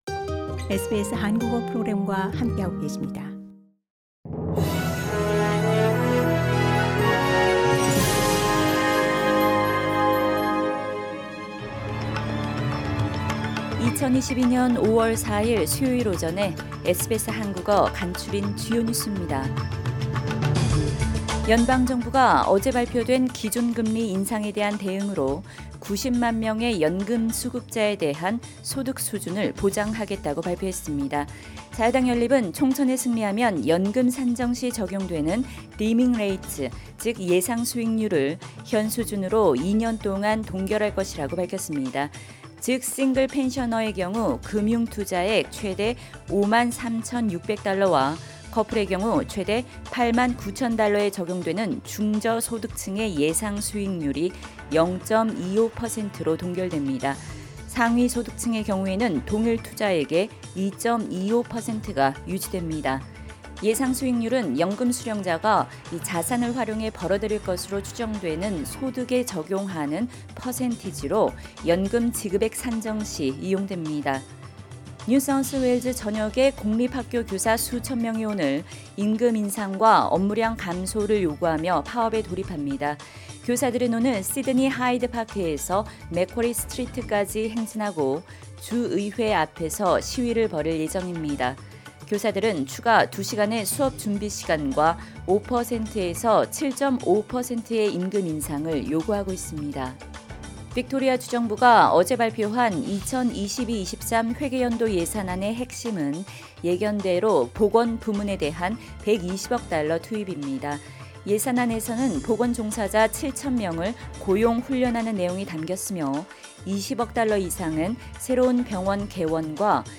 SBS 한국어 아침 뉴스: 2022년 5월 4일 수요일
2022년 5월 4일 수요일 아침 SBS 한국어 간추린 주요 뉴스입니다.